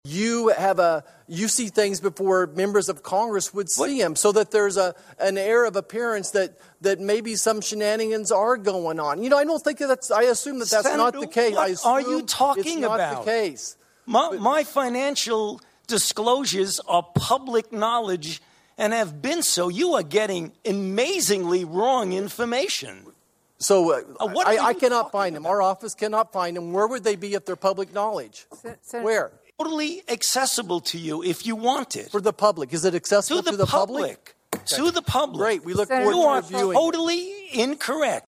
Fauci and Marshall had two tense exchanges during the hearing Tuesday.